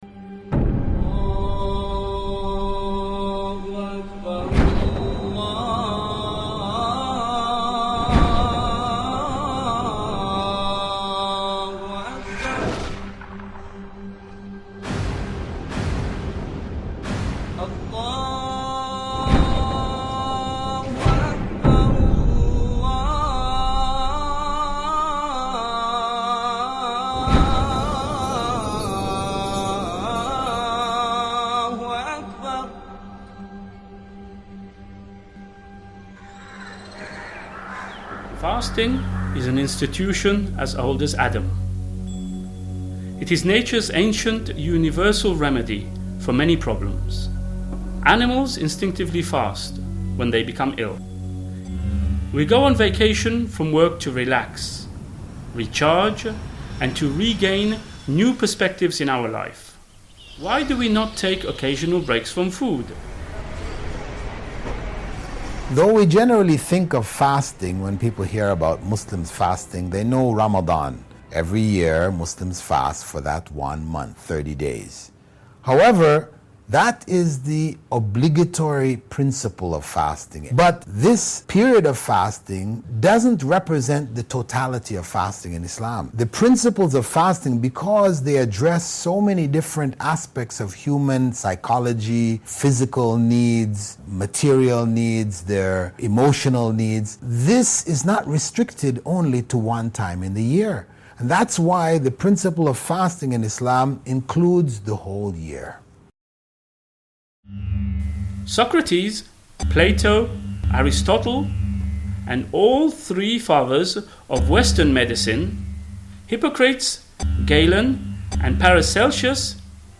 Documentary: Fasting in the Month of Ramadhan